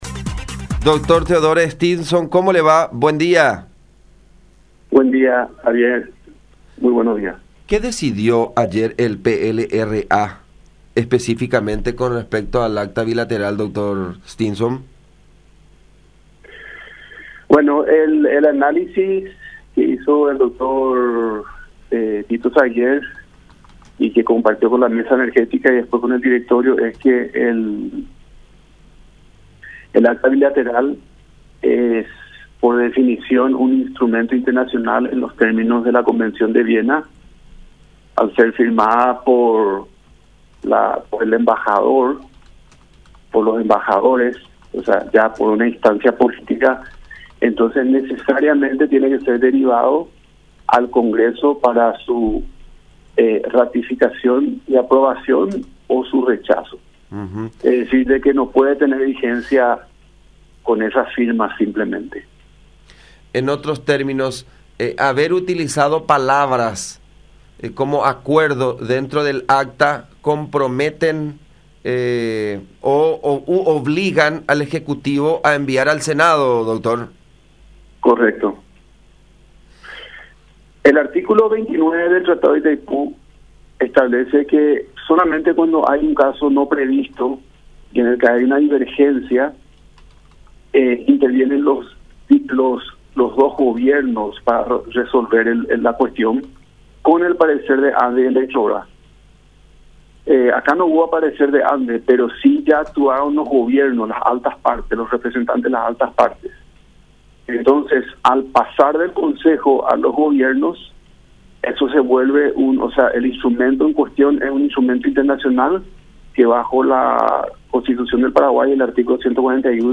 “El artículo 29 del Tratado de Itaipú establece que solamente cuando hay un caso no previsto y hay divergencia intervienen los gobiernos, con el parecer de ANDE y Eletrobras”, explicó el abogado en diálogo con La Unión, afirmando que la empresa estatal paraguaya no tuvo participación en esa decisión.